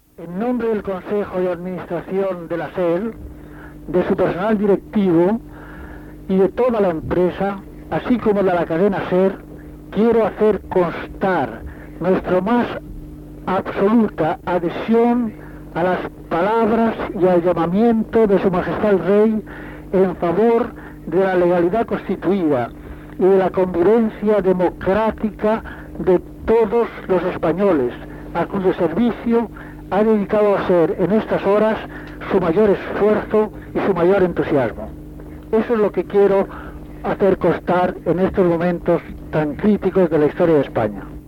A la matinada, Antonio Garrigies y Díaz Cañabate, president del Consell d'Administració de la Cadena SER, s'adhereix al discurs fet poc abans pel rei Juan Carlos I i a la Constitució espanyola
Informatiu
Extret del casset "La SER informa de pleno" publicat per la Cadena SER